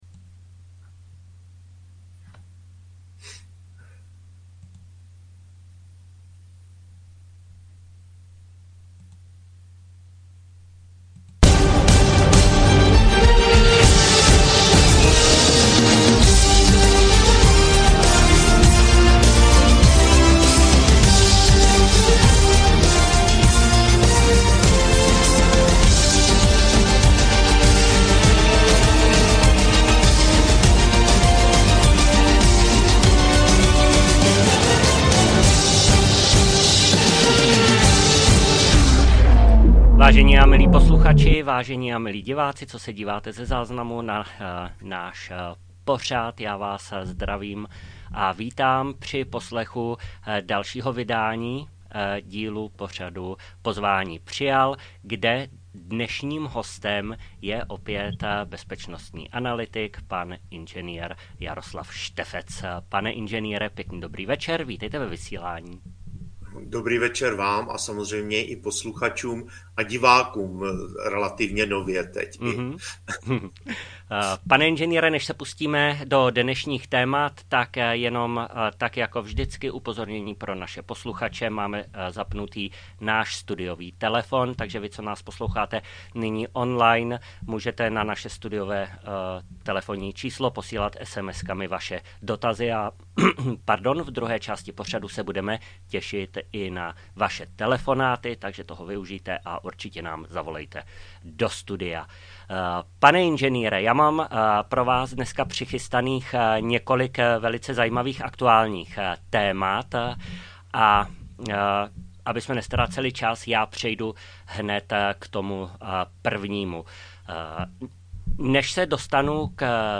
Studio Berlín, Svobodné rádio Ve speciálním vydání diskusního pořadu